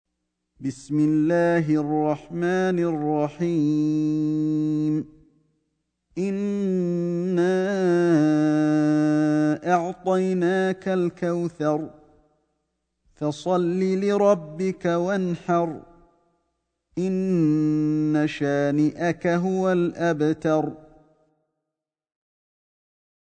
سورة الكوثر > مصحف الشيخ علي الحذيفي ( رواية شعبة عن عاصم ) > المصحف - تلاوات الحرمين